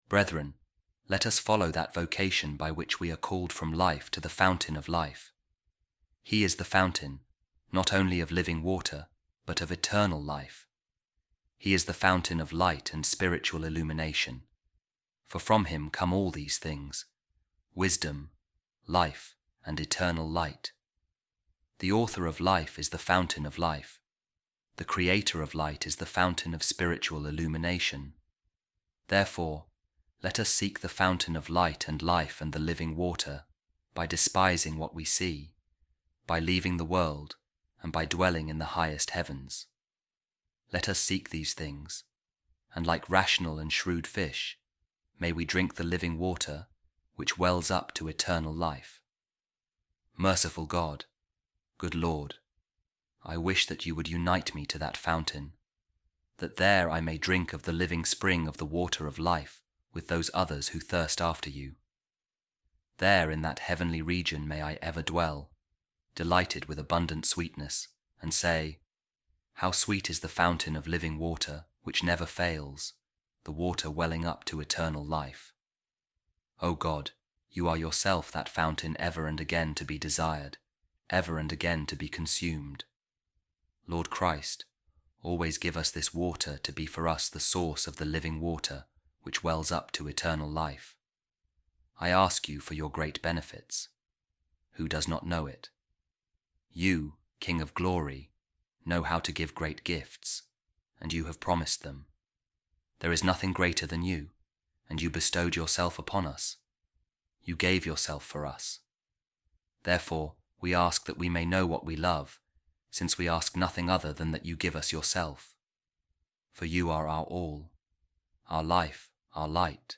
A Reading From The Instructions Of Saint Columban | You Are All Things To Us, O God